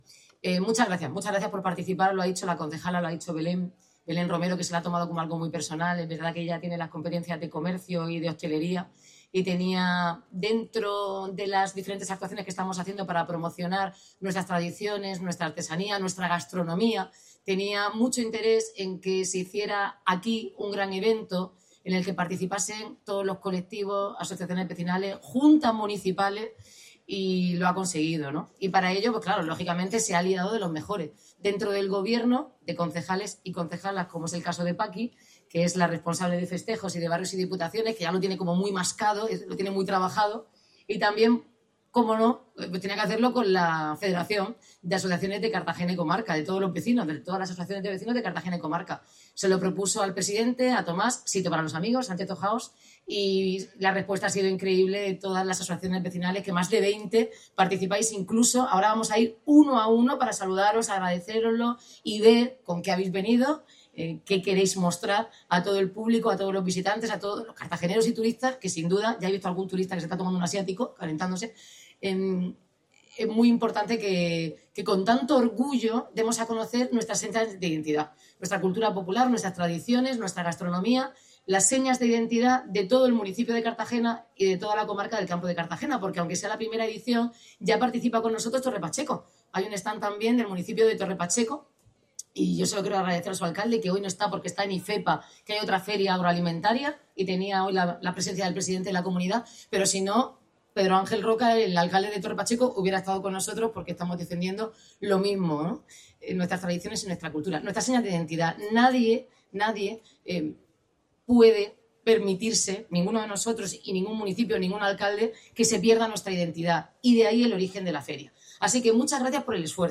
El Parque de la Rambla se ha convertido durante la jornada de este sábado en el epicentro de la gastronomía, música y artesanía cartagenera más tradicional
También han disfrutado de la música a cargo de las Cuadrillas, así como exhibiciones de trovos, esparto y bolillo, incluyendo talleres y demostraciones artesanales.